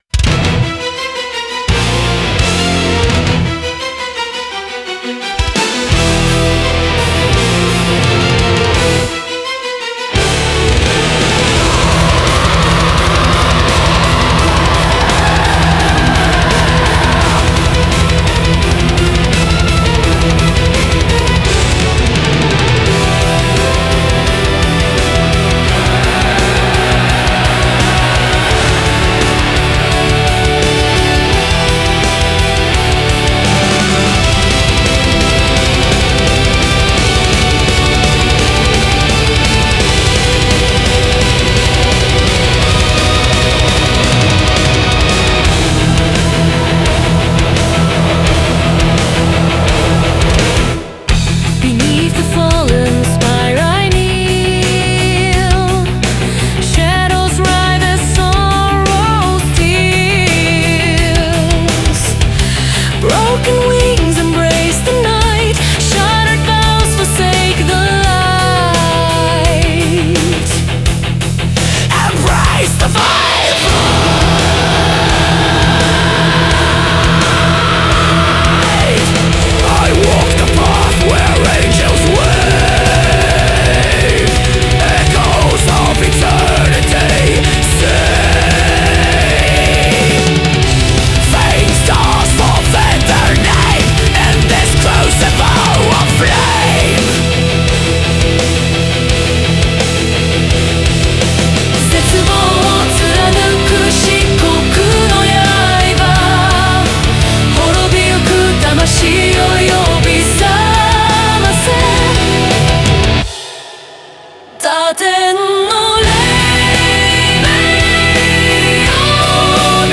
Symphonic Death Metal